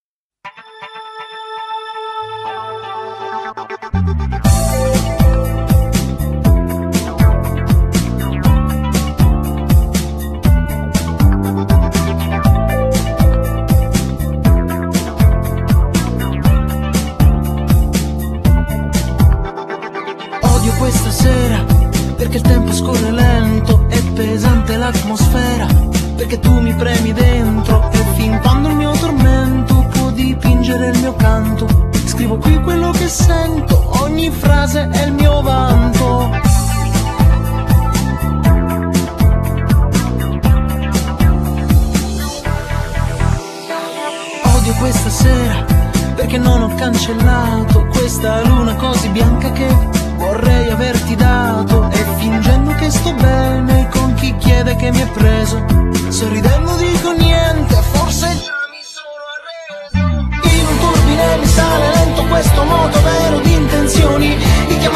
Genere : Pop
ottimo sound in simbiosi con la vove e ben eseguito.